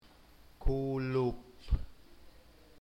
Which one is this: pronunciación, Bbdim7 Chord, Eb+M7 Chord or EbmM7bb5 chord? pronunciación